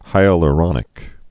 (hīə-l-rŏnĭk)